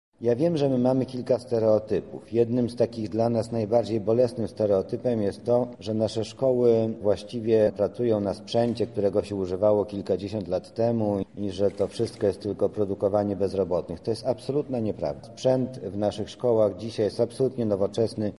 O stanie lubelskich szkół zawodowych i technicznych mówi Mariusz Banach, Zastępca Prezydenta ds. Oświaty i Wychowania.